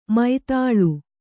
♪ mai tāḷu